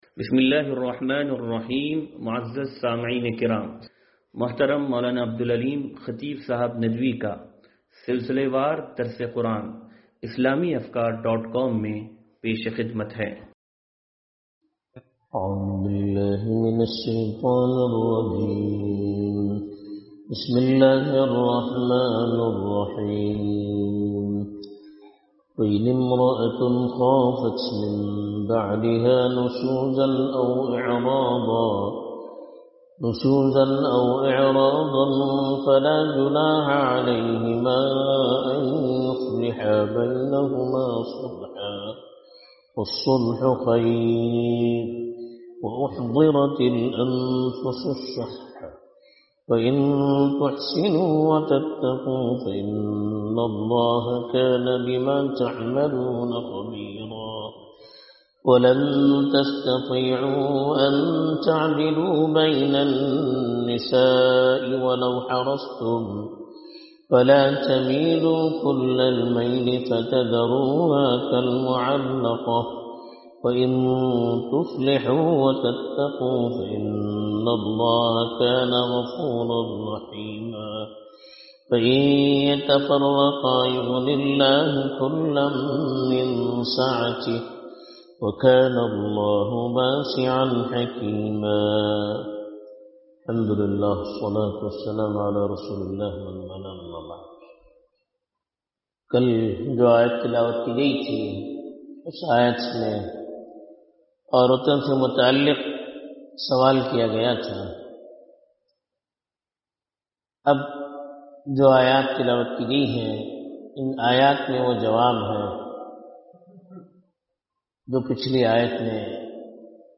درس قرآن نمبر 0399